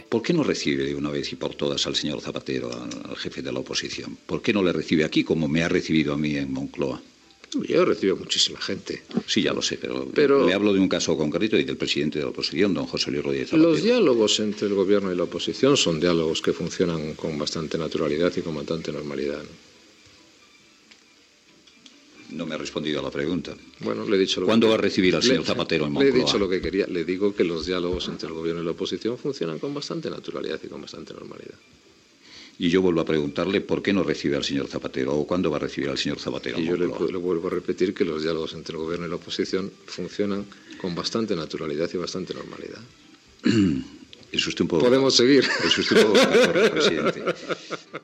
Fragment d'una entrevista al president José Maria Aznar. Del Olmo li pregunta perquè no reb al cap de l'oposició Rodríguez Zapatero.
Info-entreteniment